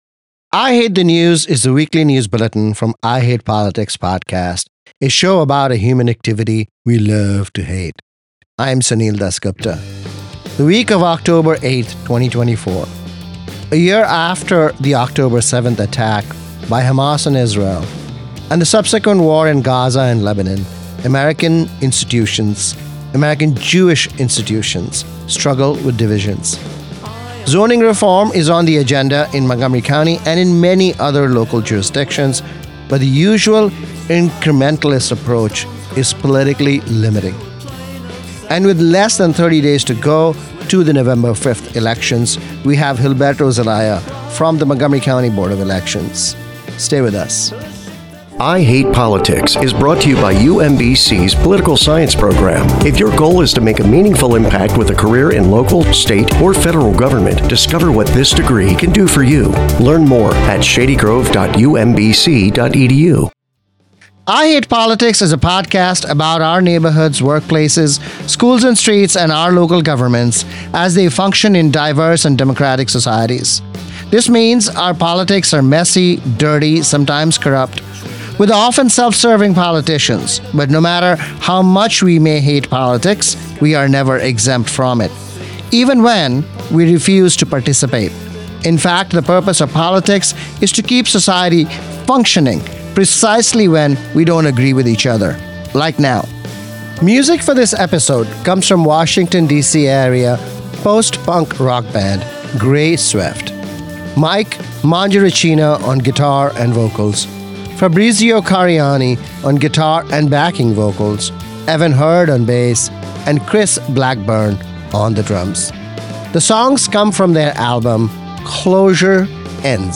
The weekly news analysis from I Hate Politics: A year after the Oct 7 attack on Israel and the subsequent war in Gaza and Lebanon, American institutions struggle to meet the moment. How the incrementalist approach to zoning reform may be holding back the housing affordability agenda. Less than 30 days to the November 5 elections, we have dates and deadlines.